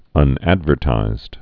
(ŭn-ădvûr-tīzd)